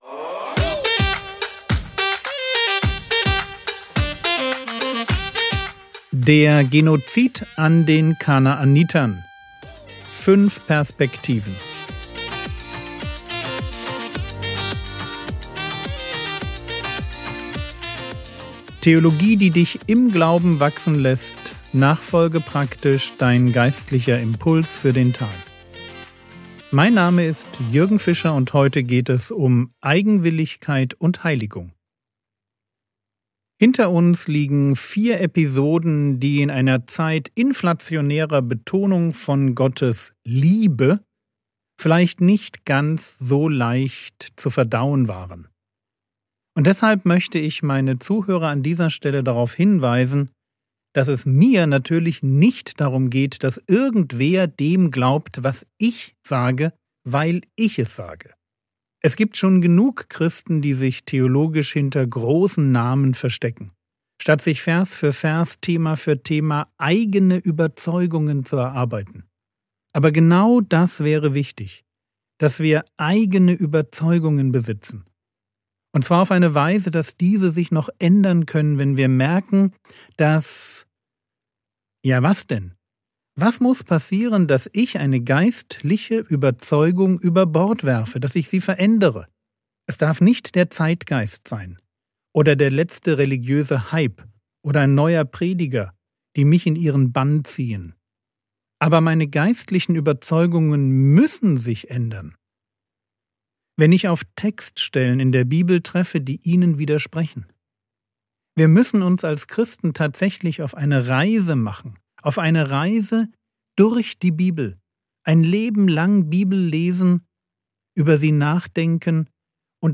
Der Genozid an den Kanaanitern (5/5) ~ Frogwords Mini-Predigt Podcast